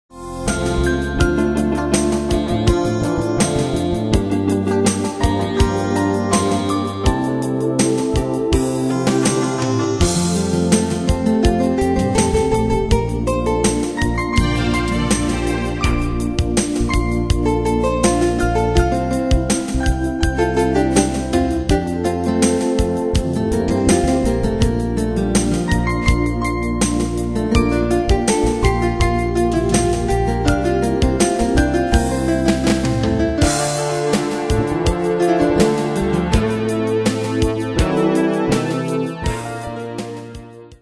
Style: PopAnglo Ane/Year: 1985 Tempo: 82 Durée/Time: 4.49
Danse/Dance: Ballade Cat Id.
Pro Backing Tracks